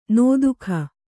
♪ nō duhkha